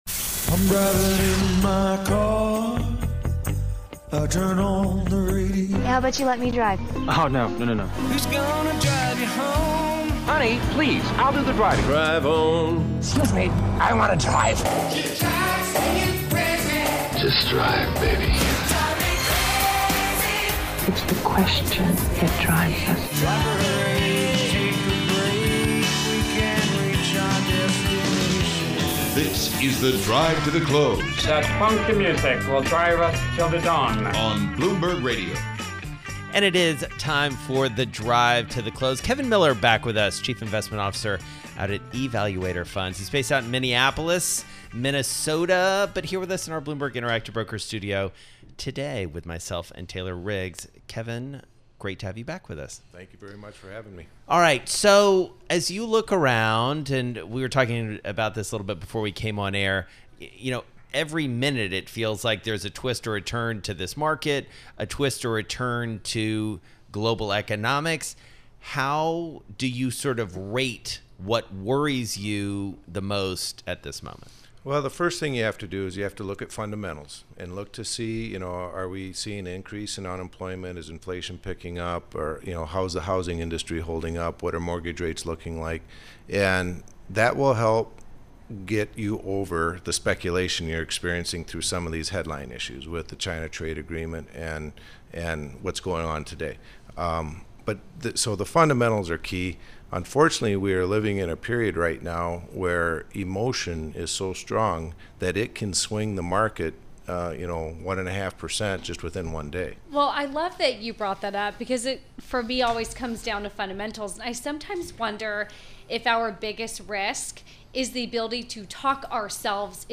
in studio with Bloomberg Radio